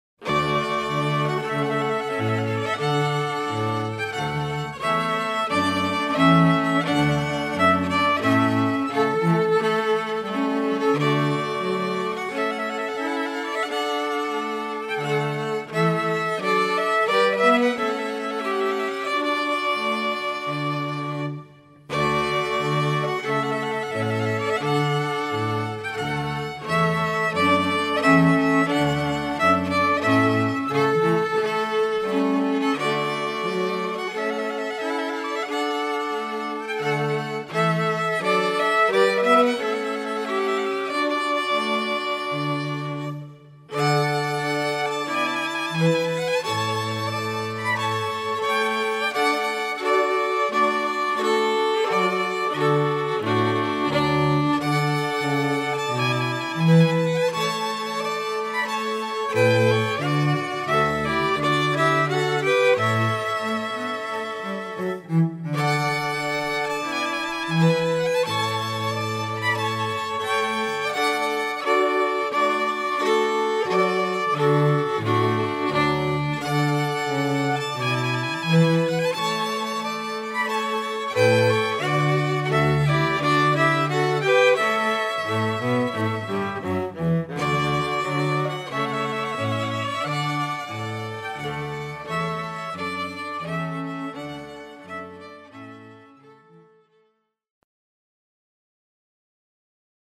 (Two Violins, Viola, & Cello)